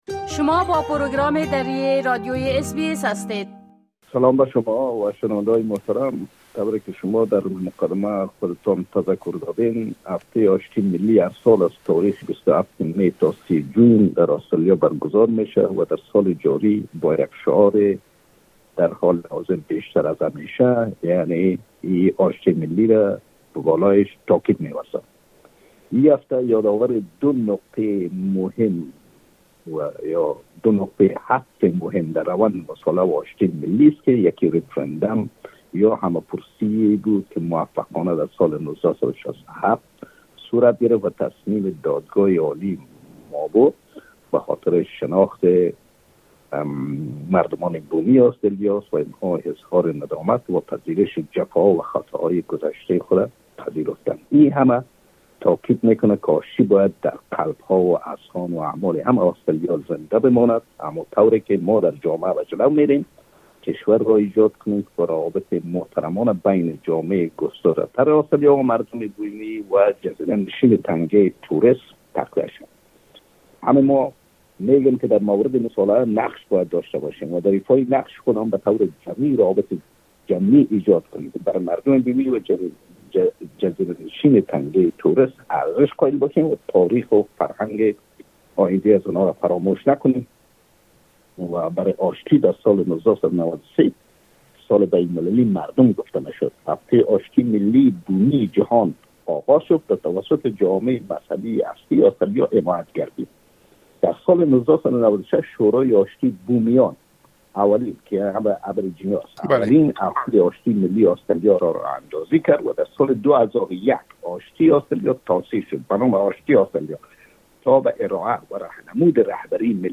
در گفتگو